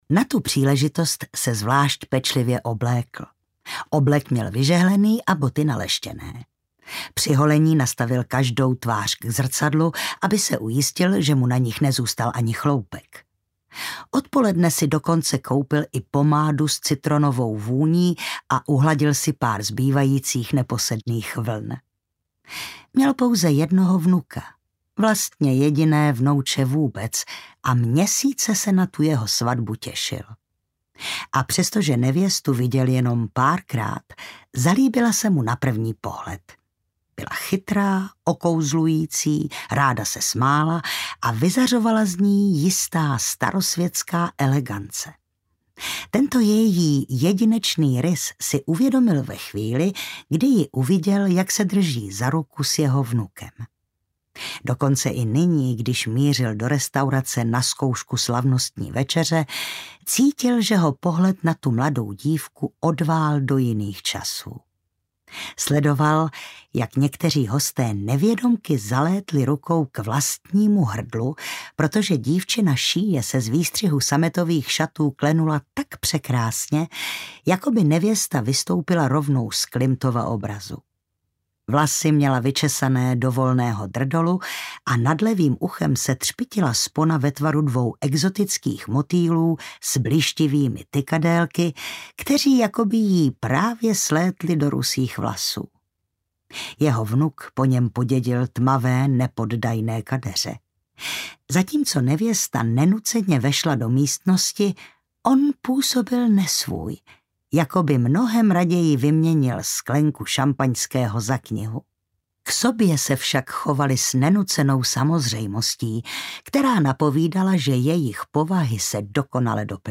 Audiokniha Ztracená manželka, kterou napsala Alyson Richman.
Ukázka z knihy
ztracena-manzelka-audiokniha